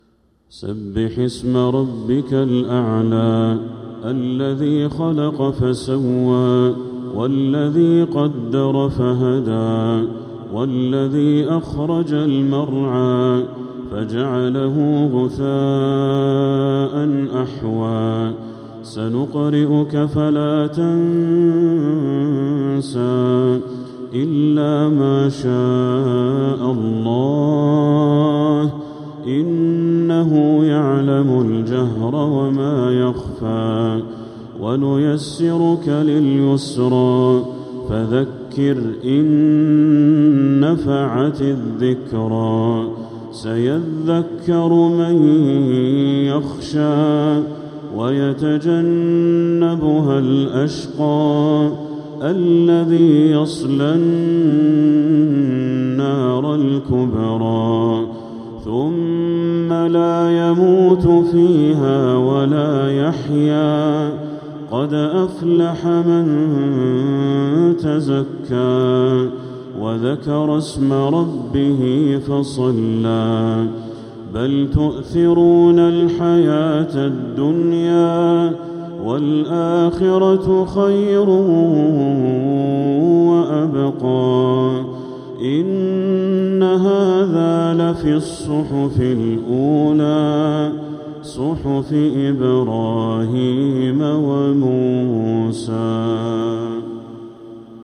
سورة الأعلى كاملة | محرم 1447هـ > السور المكتملة للشيخ بدر التركي من الحرم المكي 🕋 > السور المكتملة 🕋 > المزيد - تلاوات الحرمين